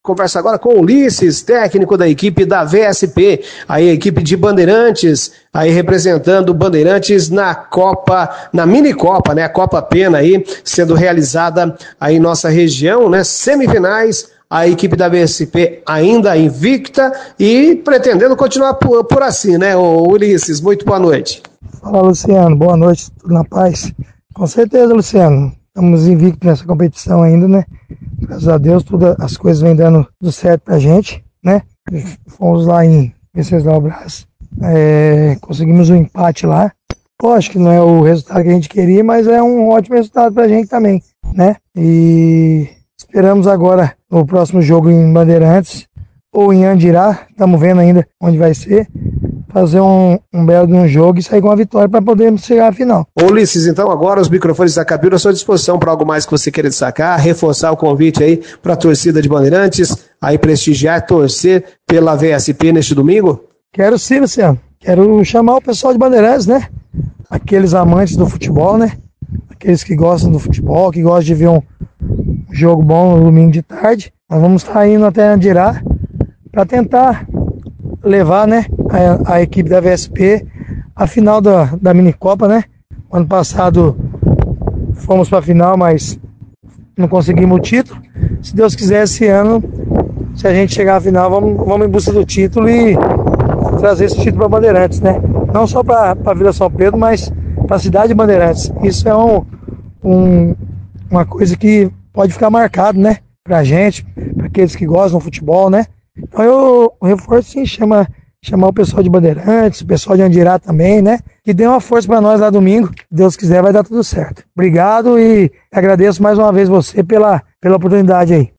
BOLETIM-MINI-COPA01.mp3